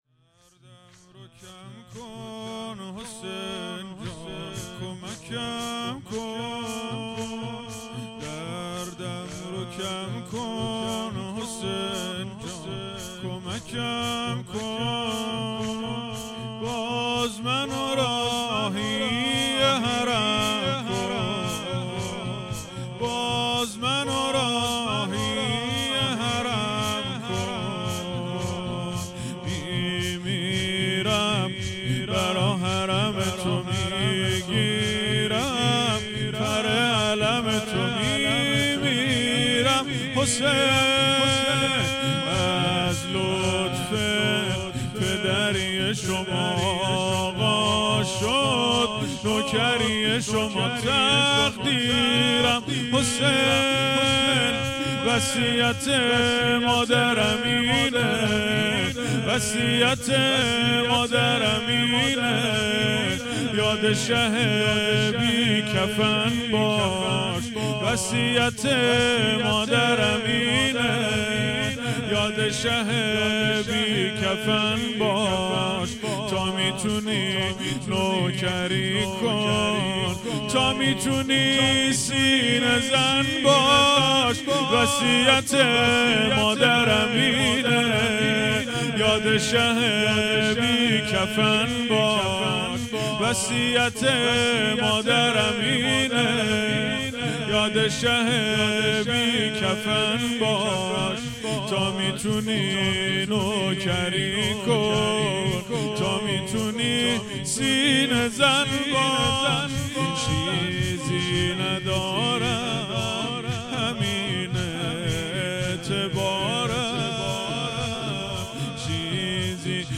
روضه هفتگی ۶ آبان ۱۴۰۰